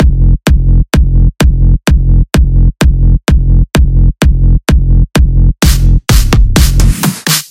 阿拉伯语EDM鼓1
描述：在FlStudio制造。与阿拉伯文EDM LEAD完全吻合;)
Tag: 128 bpm Electronic Loops Drum Loops 1.27 MB wav Key : F